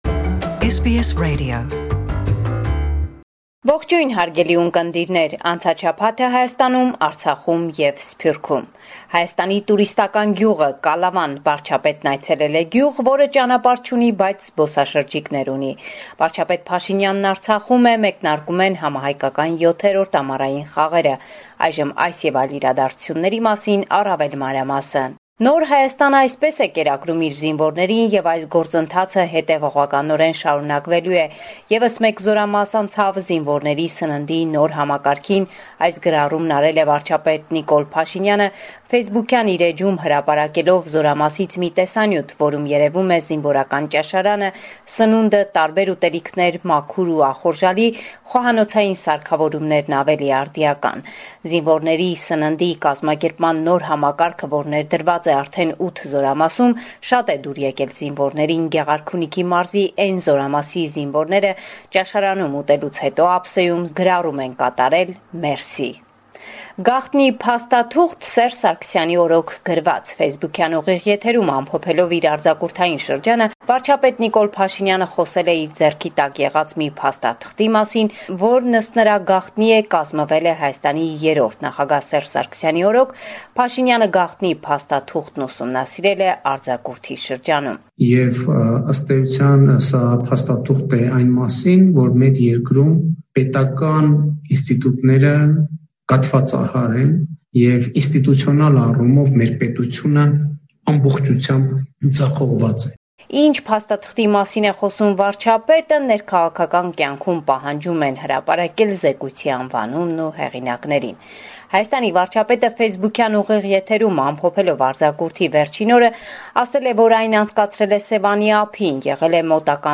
Latest News – 6 August 2019